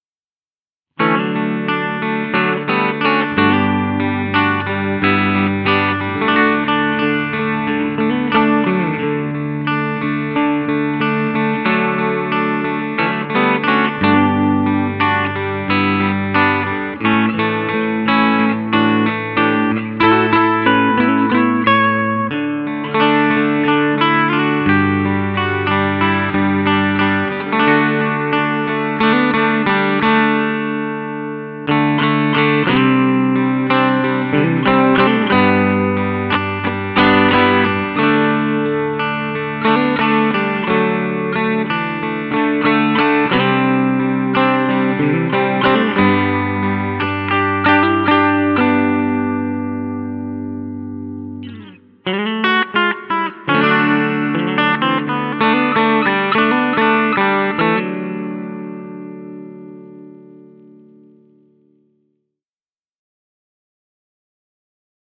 Deux modes Booster avec le switch Treble/Natural.
Ce Booster analogique permet d’amplifier le signal audio d’une guitare ou d’une basse et d’en améliorer la musicalité. Il produit un boost entièrement transparent et permet de conserver le SON original.